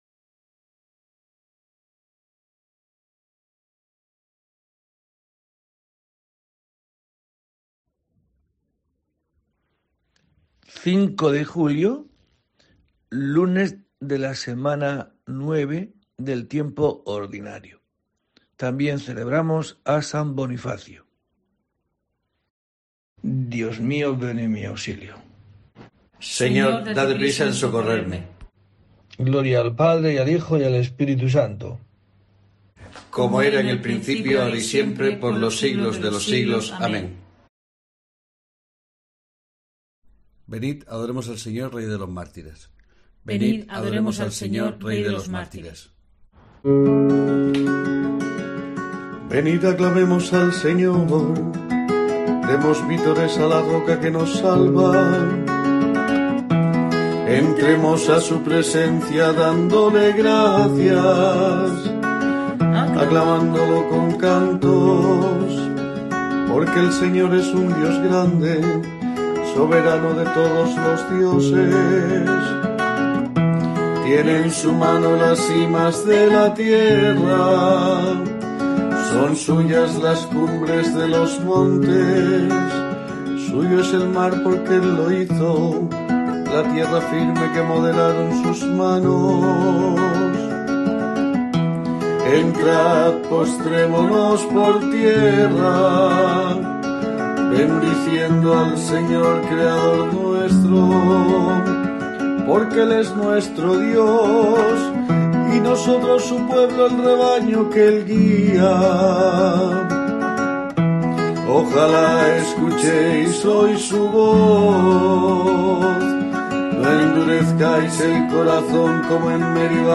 5 de junio: COPE te trae el rezo diario de los Laudes para acompañarte